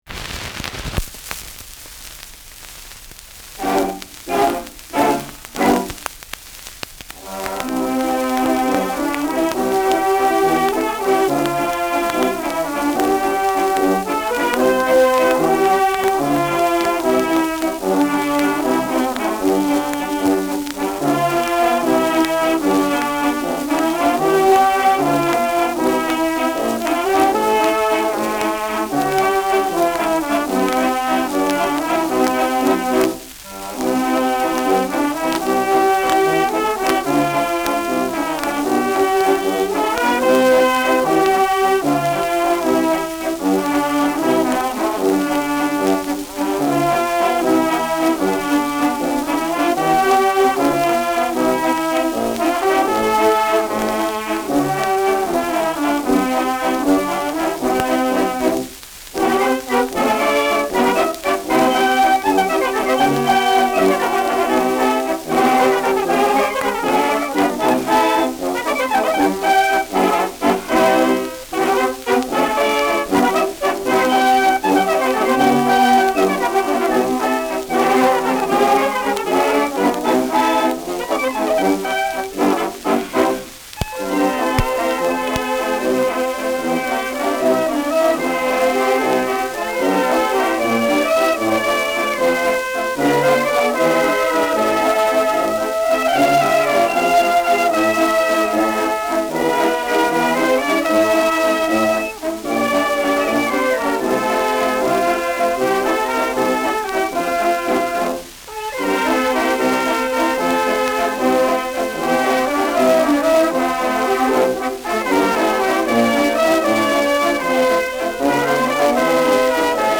Schellackplatte
[Berlin] (Aufnahmeort)